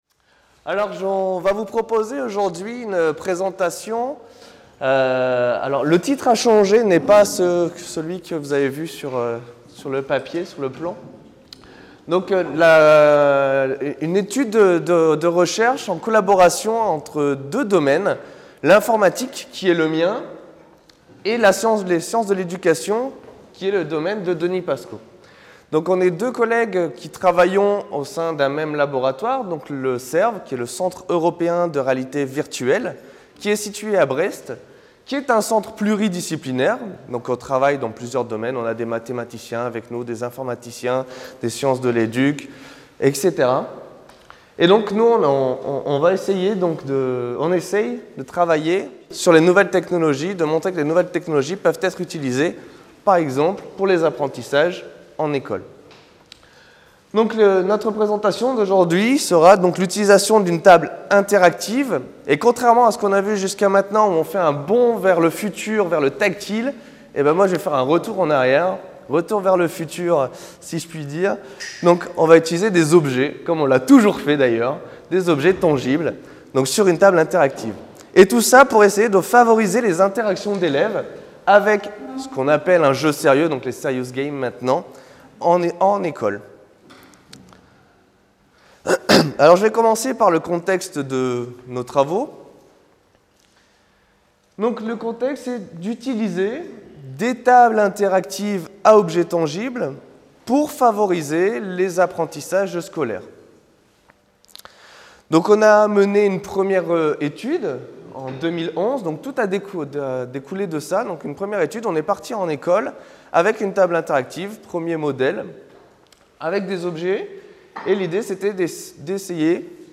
Journée d’étude organisée par l’EHESS en collaboration avec le ministère de l’éducation nationale 8 avril 2014, Salle des Conférences, Lycée Henri IV, 75005 Paris Maintenant, les objets communicants font partie de l’environnement des enfants dès leur naissance. Si ces objets ne sont pas entre les mains des jeunes enfants, ils sont omniprésents chez leurs entourages et dans le monde matériel et culturel qui les entoure.